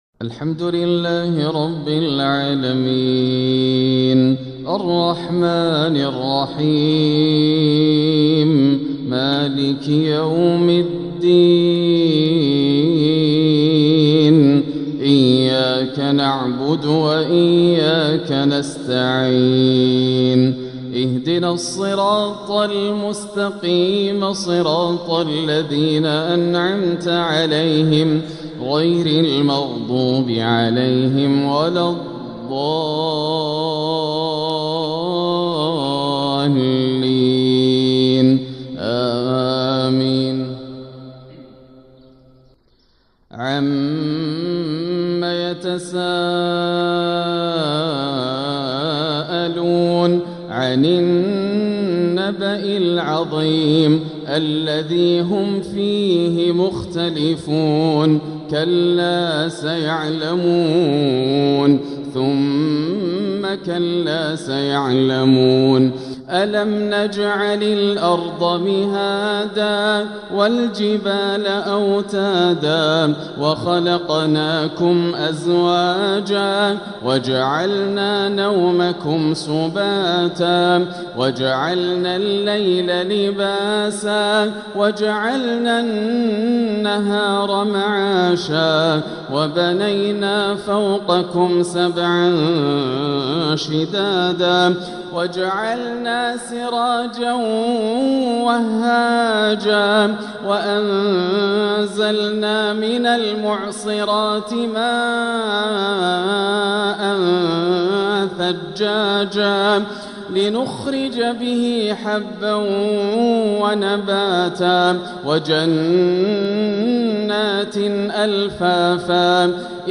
العقد الآسر لتلاوات الشيخ ياسر الدوسري تلاوات شهر شوال عام ١٤٤٦ هـ من الحرم المكي > سلسلة العقد الآسر من تلاوات الشيخ ياسر > المزيد - تلاوات ياسر الدوسري